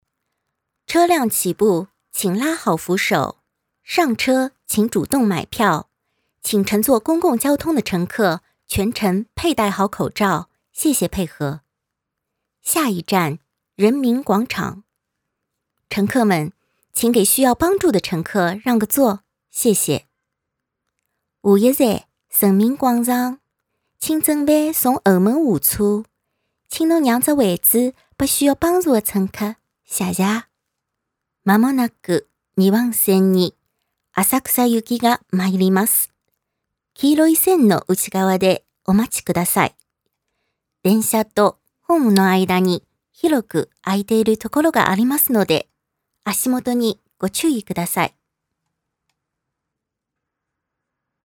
国沪日三语报站